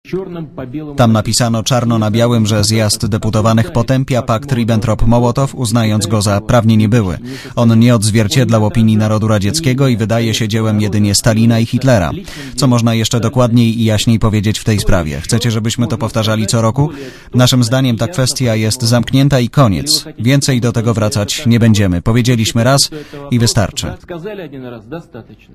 Prezydent Władimir Putin oświadczył na konferencji prasowej na Kremlu po zakończeniu szczytu Rosja-UE, że Moskwa uważa sprawę paktu Ribbentrop-Mołotow za zamkniętą.